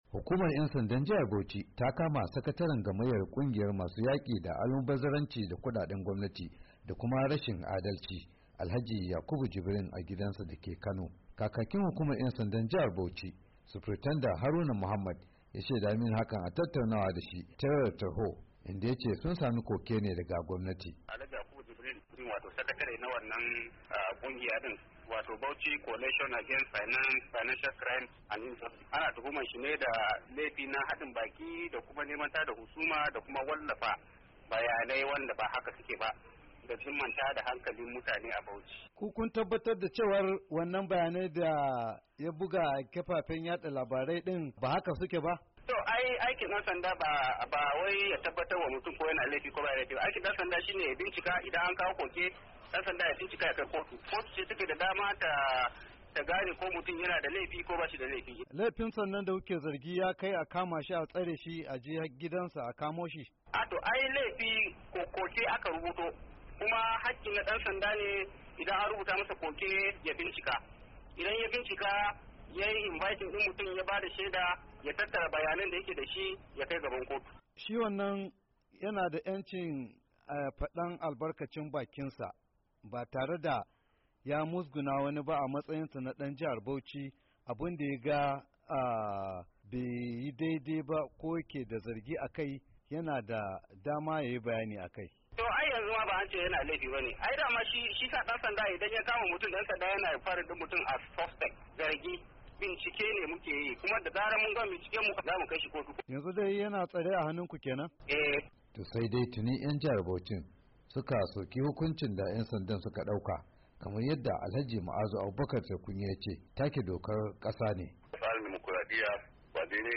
da cikakken rahoton: